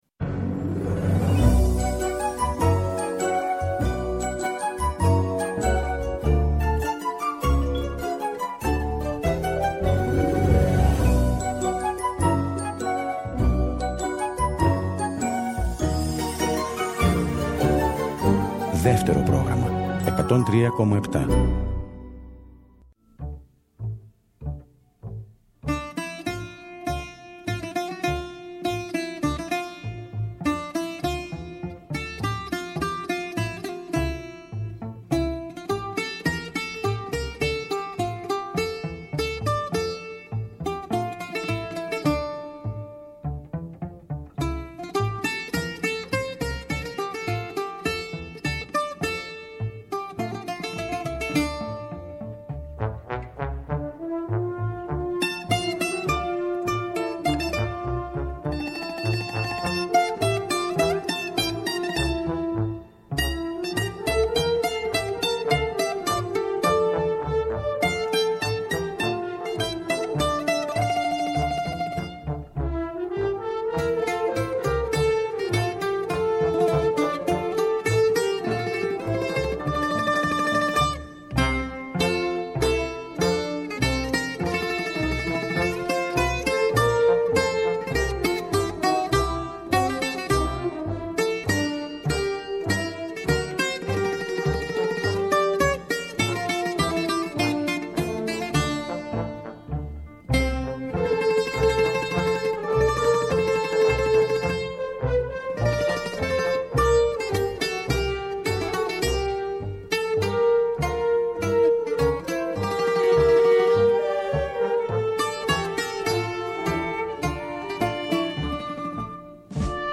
κάθε Σάββατο και Κυριακή στις 19.00 έρχεται στο Δεύτερο Πρόγραμμα με ένα ραδιοφωνικό – μουσικό road trip.